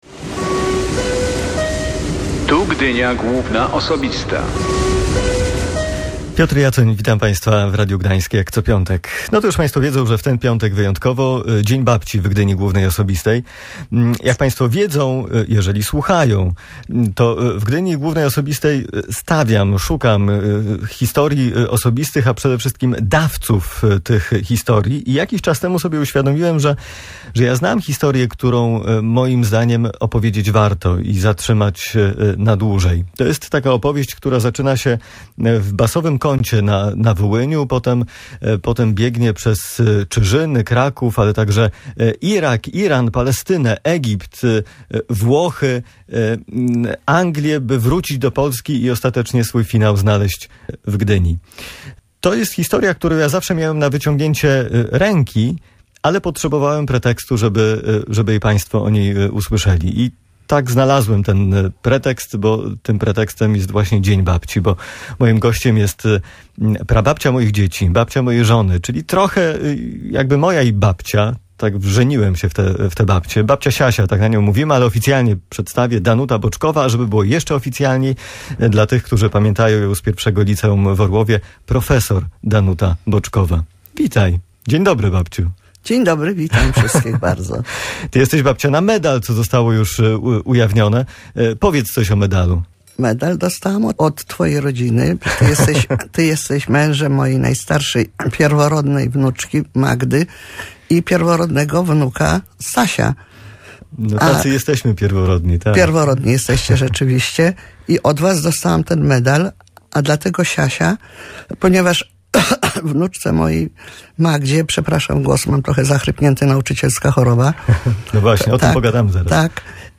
Dzień Babci był pretekstem do zaproszenia jej do studia. Do Gdyni bohaterka audycji przyjechała po wojnie z Małopolski.